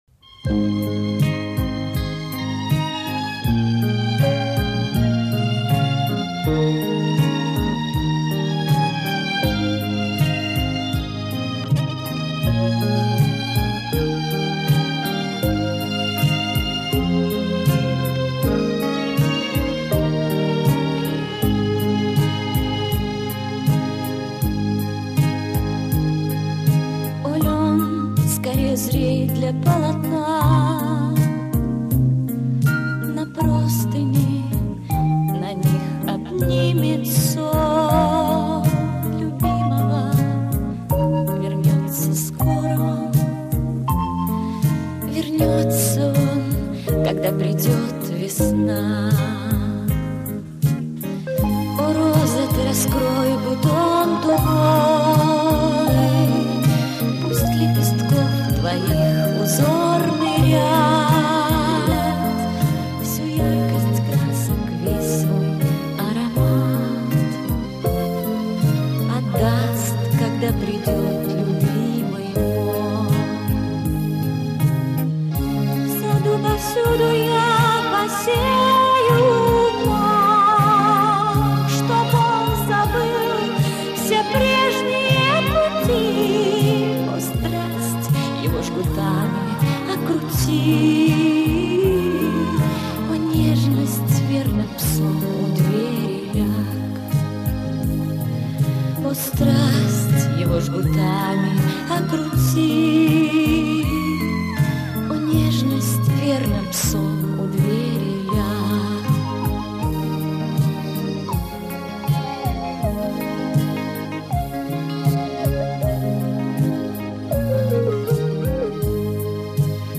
И КАЧЕСТВО ОТВРАТИТЕЛЬНОЕ.